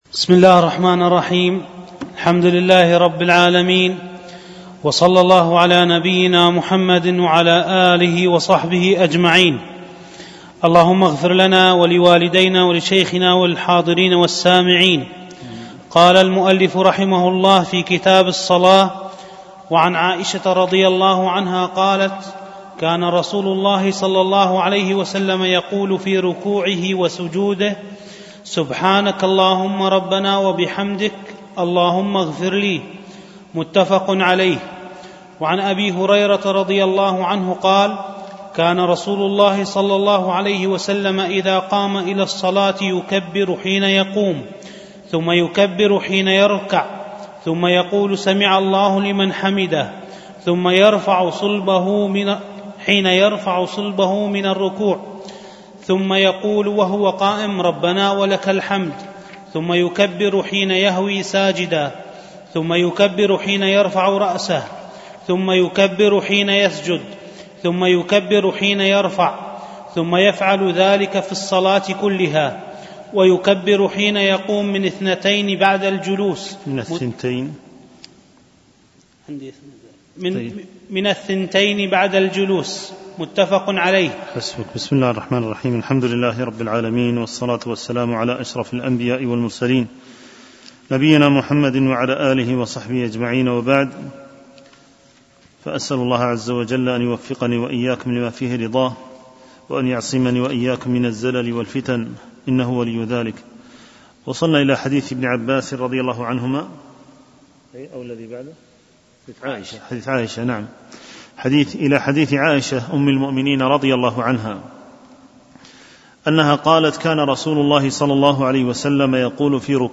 شرح كتاب بلوغ المرام من أدلة الأحكام - الدرس الأربعون
دروس مسجد عائشة